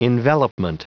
Prononciation du mot envelopment en anglais (fichier audio)
Prononciation du mot : envelopment